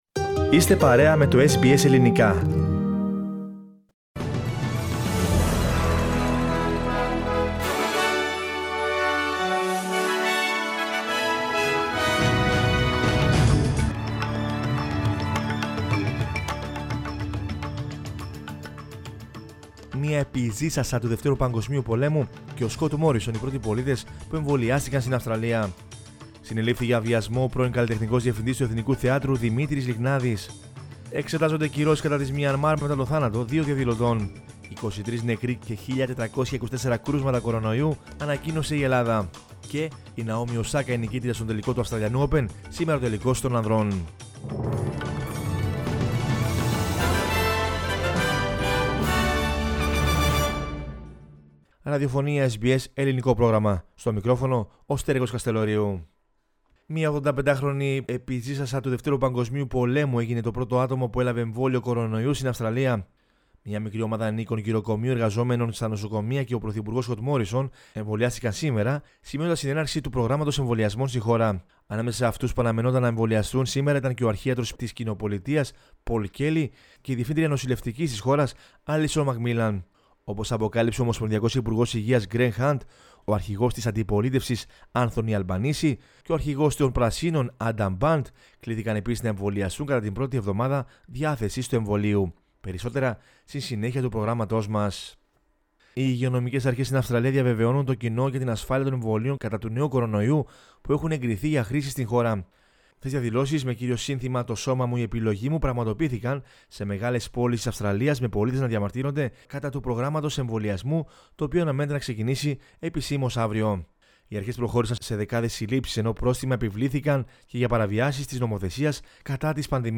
News in Greek from Australia, Greece, Cyprus and the world is the news bulletin of Sunday 21 February 2021.